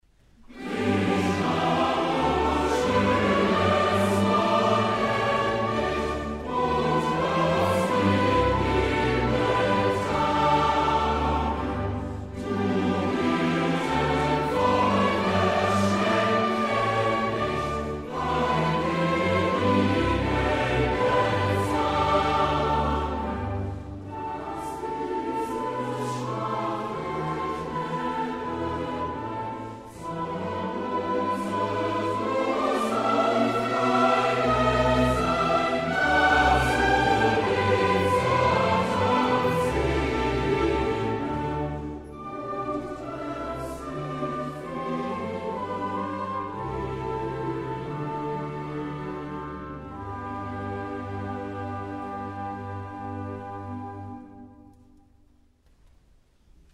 Dezember - MünchenKlang e.V. Chor und Orchester
Aufnahme vom 13.12.2014 in der Großen Aula der LMU, München